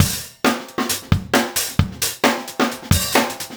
drums01.wav